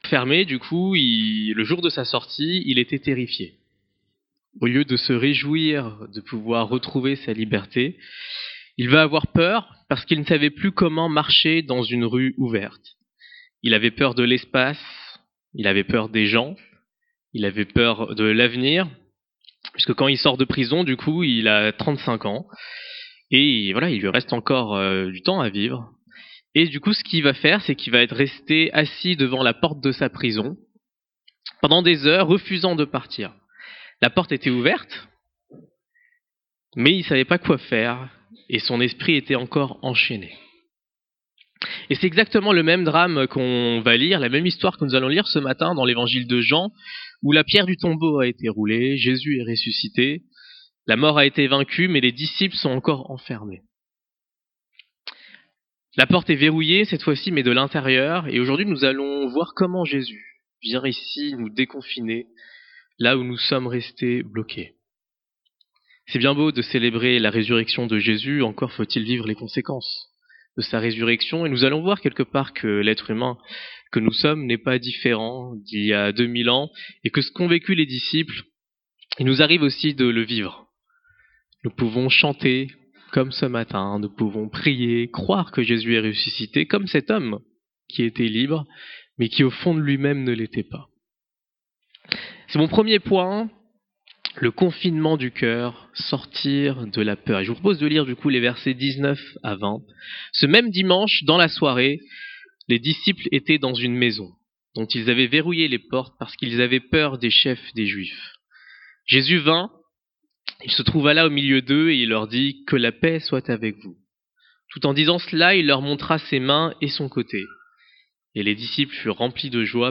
Information : veuillez nous excuser pour les quelques secondes manquantes de l’introduction